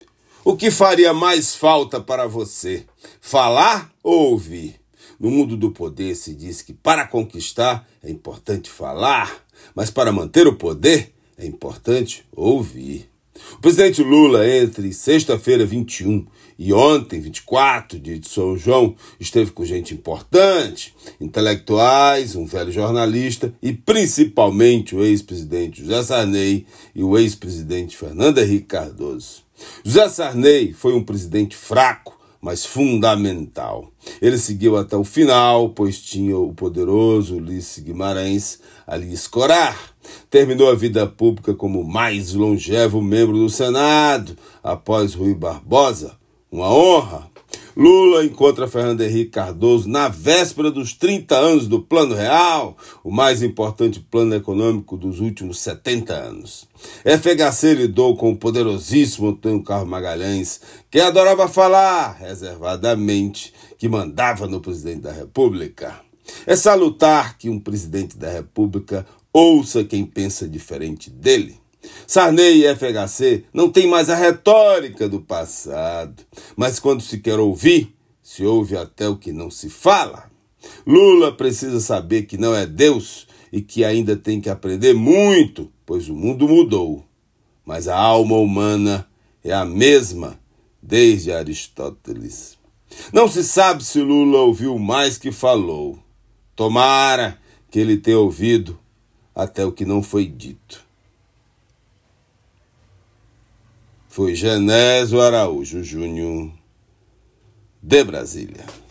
Comentário desta terça-feira